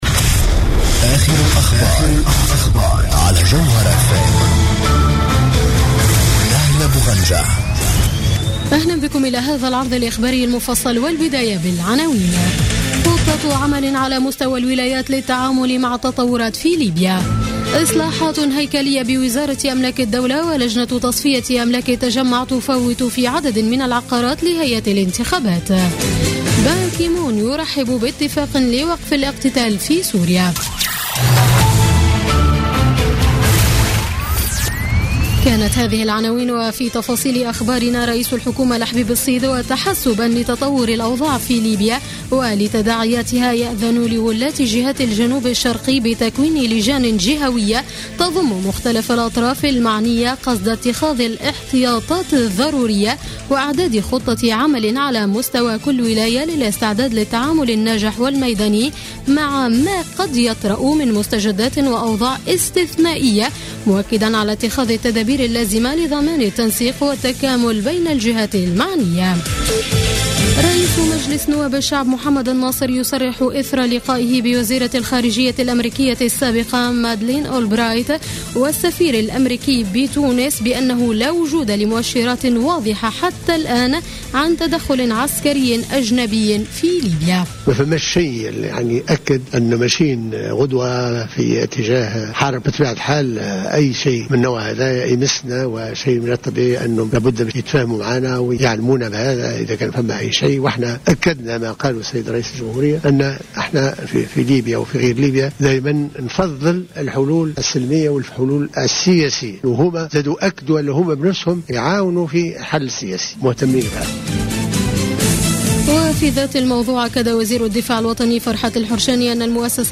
نشرة أخبار منتصف الليل ليوم السبت 13 فيفري 2016